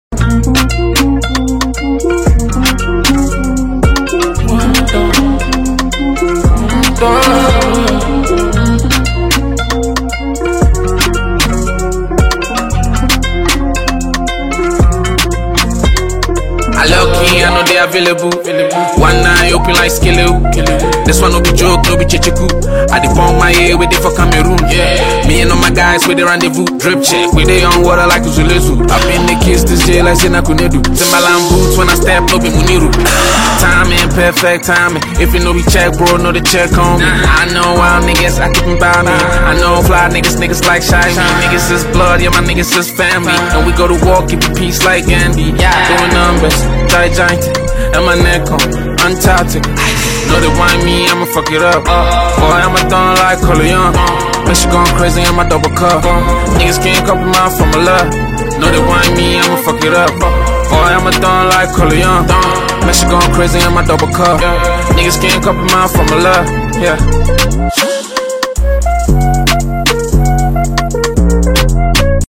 Ghana Music
boom-bap and drill-infused energy